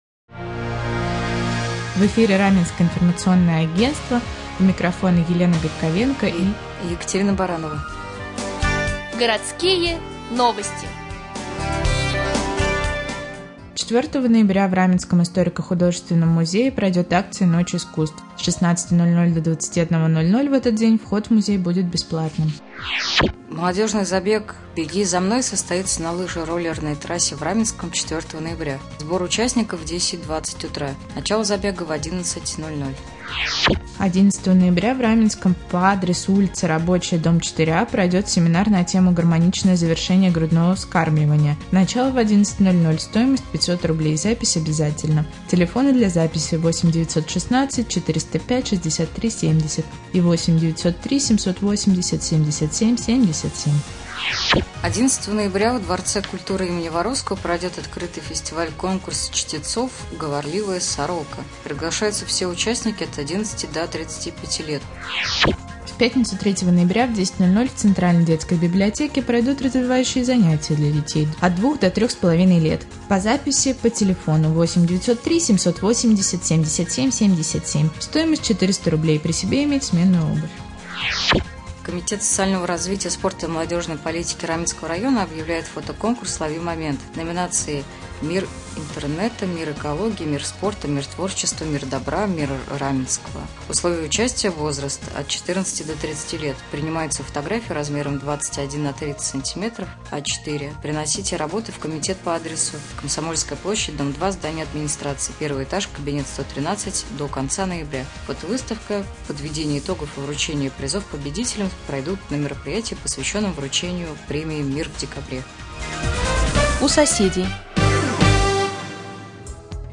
Сегодня в новостном выпуске на Раменском радио Вы узнаете, где в Раменском пройдет семинар о грудном вскармливании и развивающие занятия для детей, а также последние областные новости и новости соседних районов.